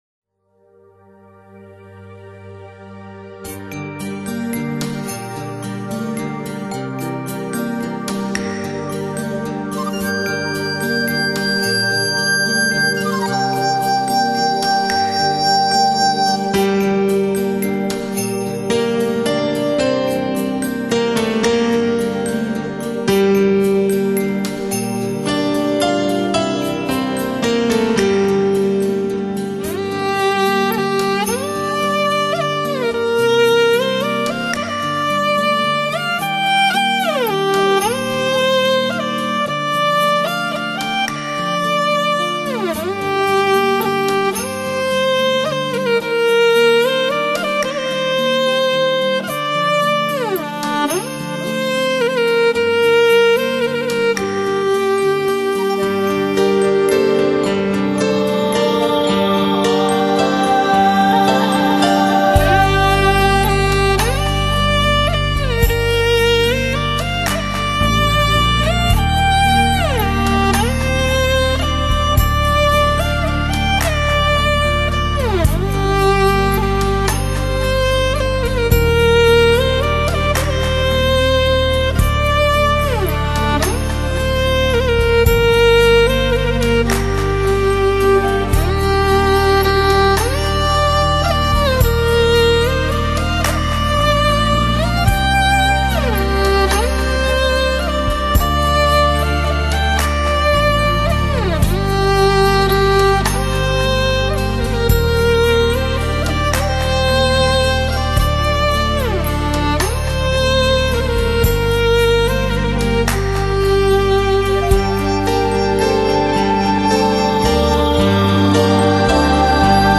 中国原创新世纪音乐作品系列精选,极品人声发烧天碟,极度发烧的真谛....